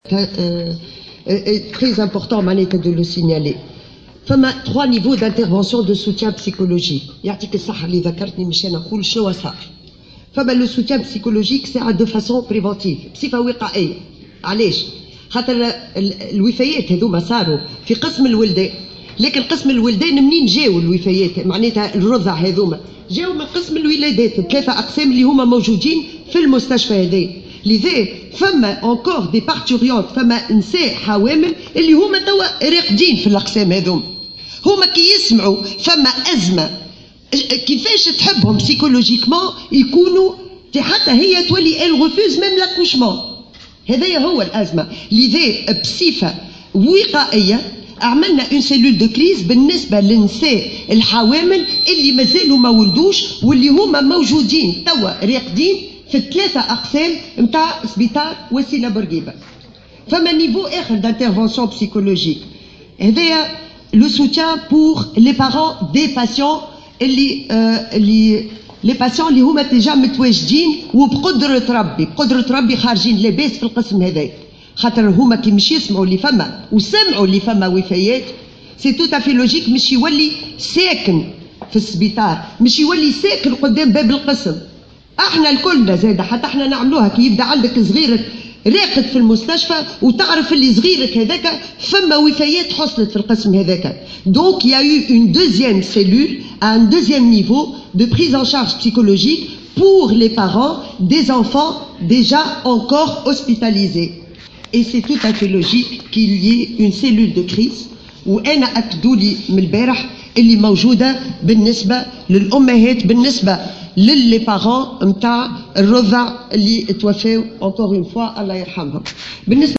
أكدت وزيرة الصحة بالنيابة سنية بالشيخ، في ندوة صحفية اليوم الاثنين أن هناك اهتماما بالجانب النفسي للضحايا من الأولياء الذين فقدوا أطفالهم في حادثة 11 رضيعا.